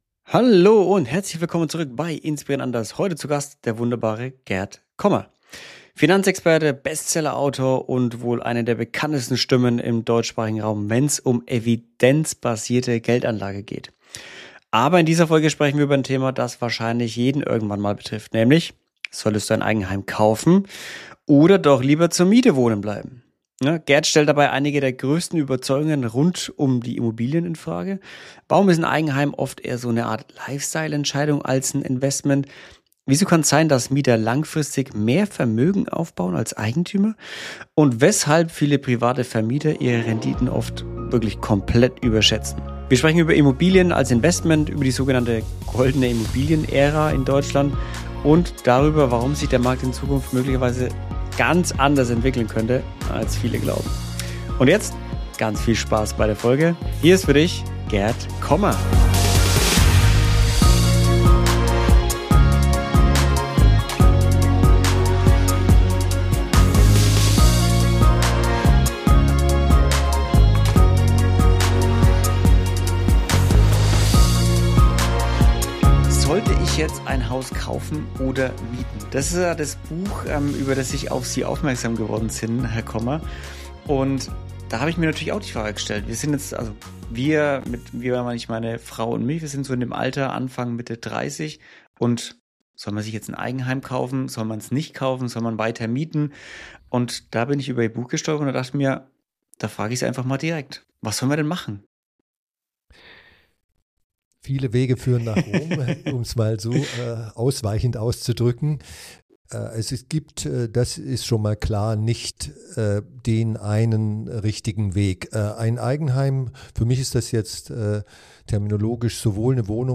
In dieser Folge zu Gast: Gerd Kommer – Finanzexperte, Bestsellerautor und einer der bekanntesten Verfechter evidenzbasierter Geldanlage im deutschsprachigen Raum.